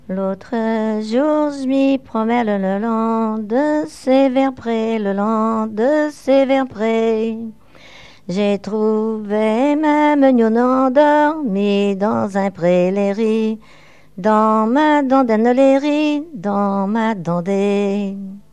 danse : ronde à la mode de l'Epine